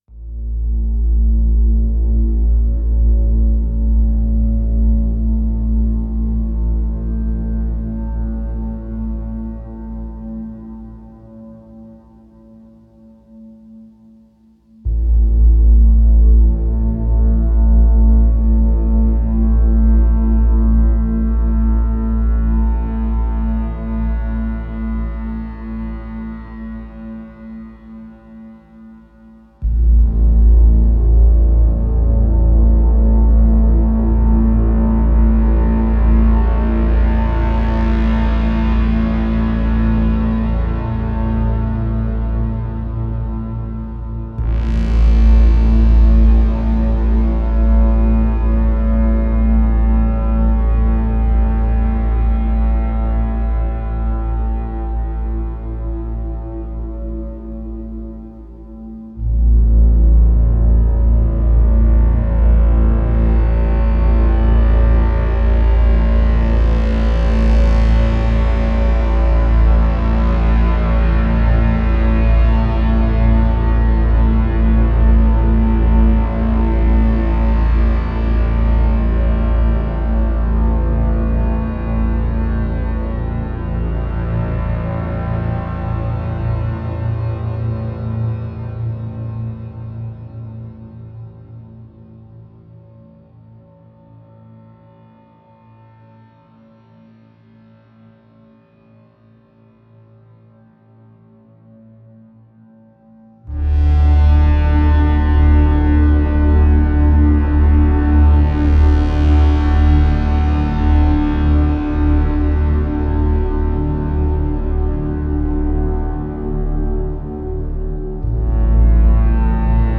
Waves of cinematic synth swells form a foreboding ambience.